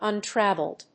/`ʌntrˈæv(ə)ld(米国英語)/